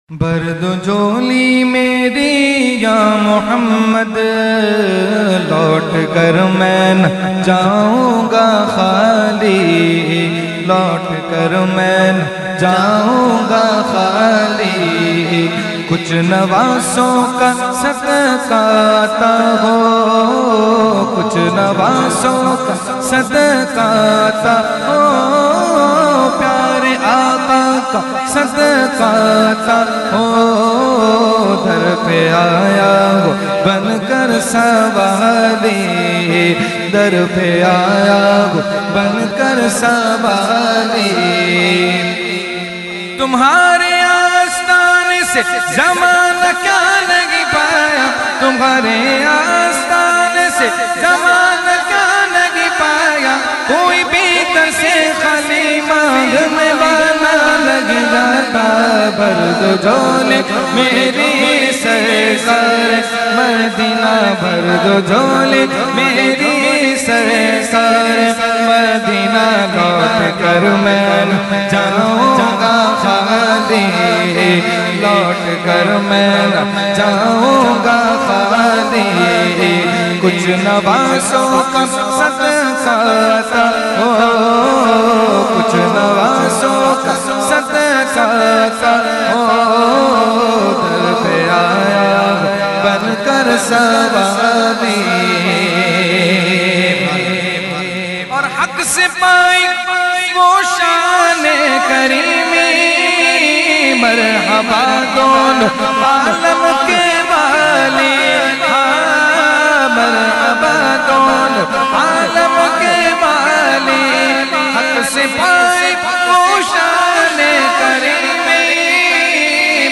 9 Roza Mehfil e Muharram ul Haram held on 1st Muharram ul Haram to 9th Muharram ul Haram at Jamia Masjid Ameer Hamza Nazimabad Karachi.
Category : Naat | Language : UrduEvent : Muharram 2021